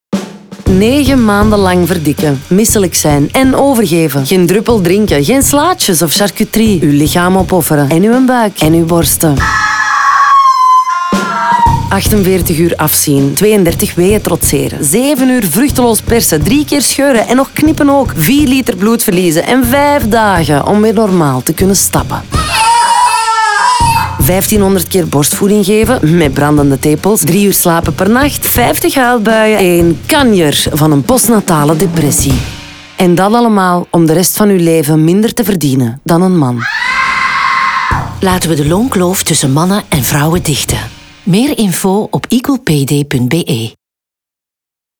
EqualPayDay-Radio-DEF-NL-45s.wav